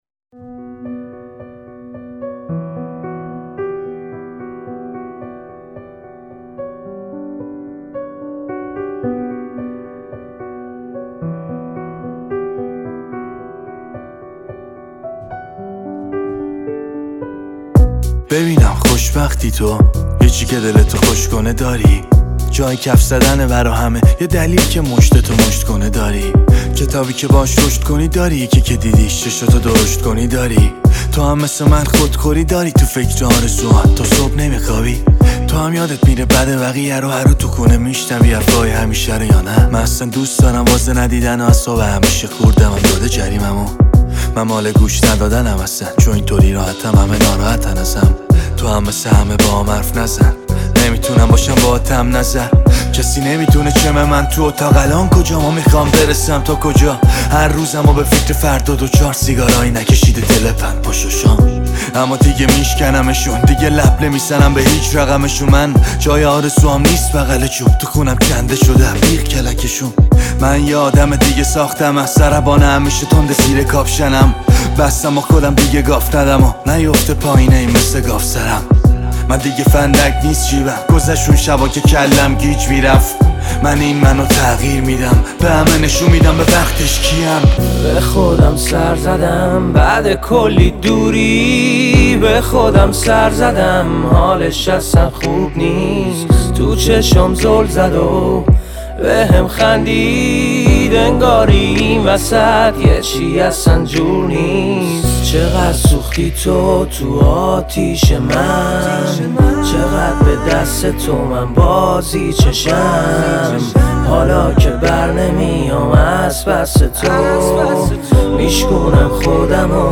• موسیقی رپ ایرانی